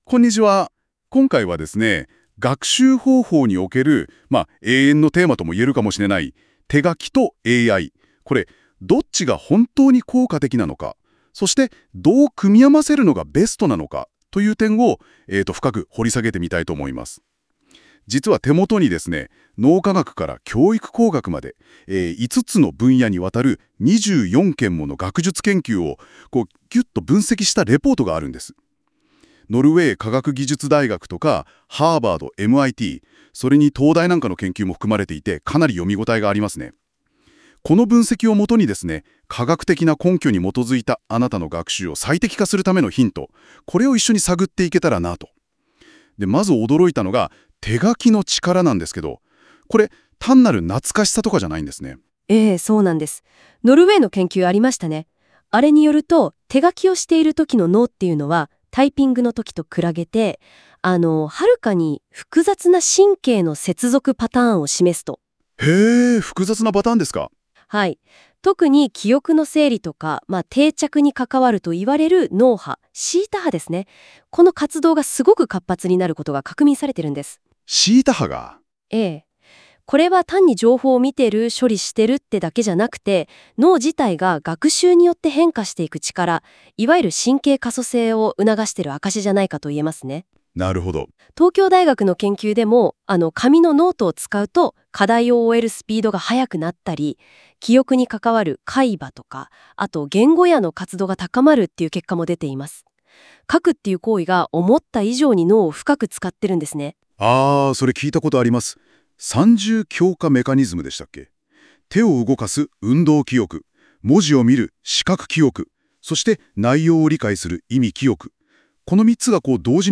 忙しい方のために、この記事をポッドキャスト形式で解説しています。
※ この音声は Google NotebookLM を使用して記事内容から生成されています。